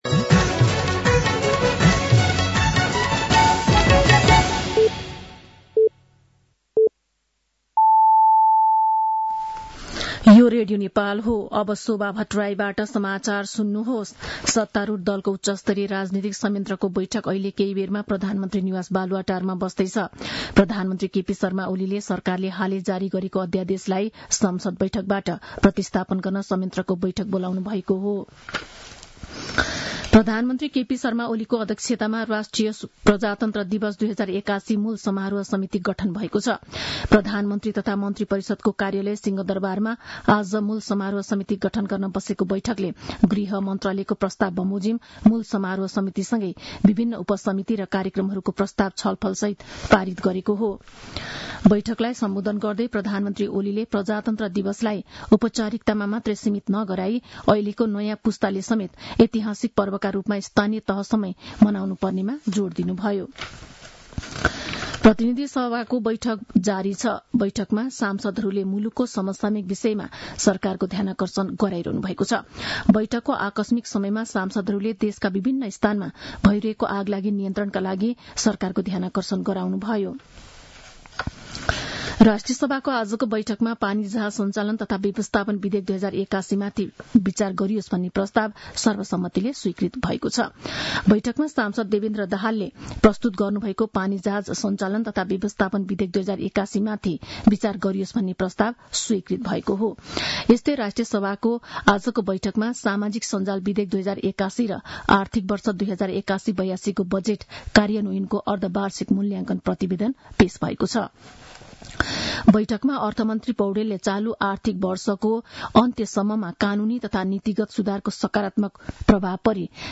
साँझ ५ बजेको नेपाली समाचार : २८ माघ , २०८१
5-pm-news-1.mp3